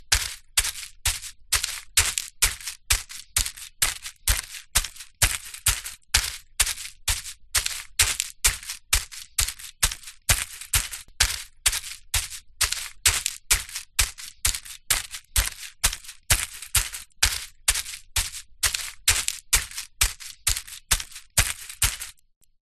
Звуки кенгуру
Бег кенгуру прыжки